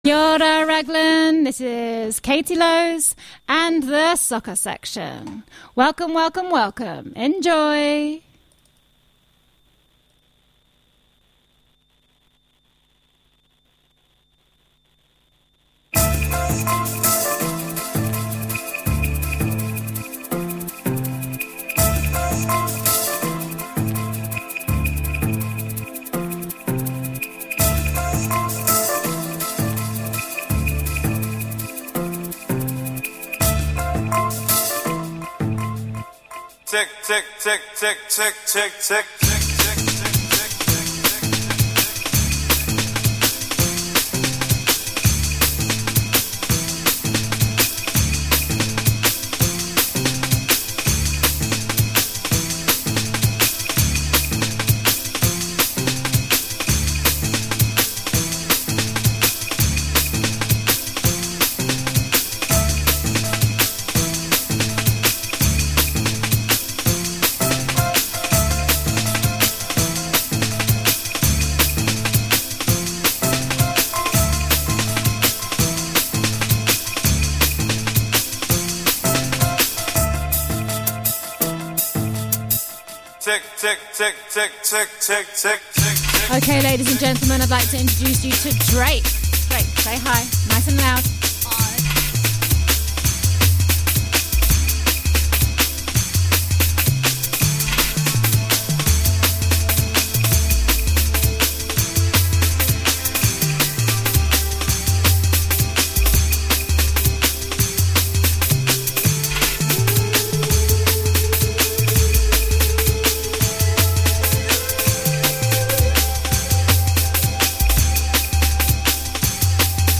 Join us for silly sounds, great tunes and soccer yarns.